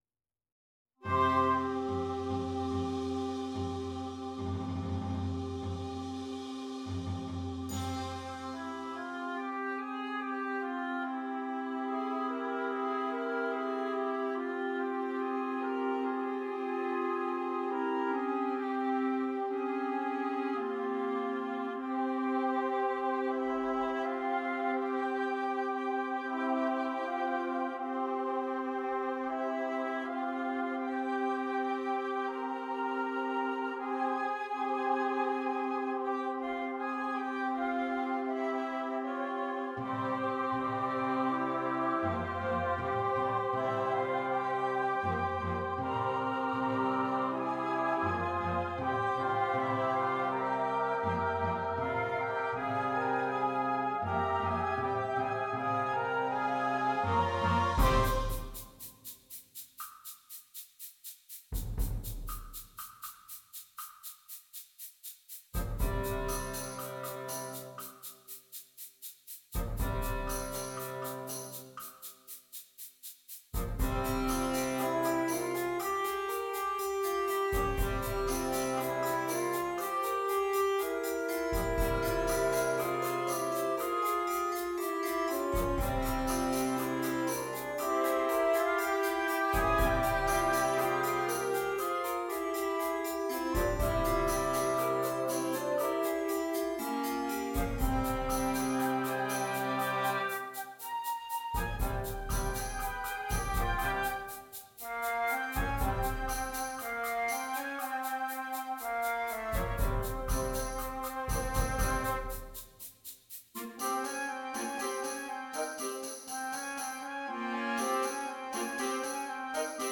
Interchangeable Woodwind Ensemble
PART 1 - Flute, Clarinet, Alto Saxophone
PART 6 - Bass Clarinet, Bassoon, Baritone Saxophone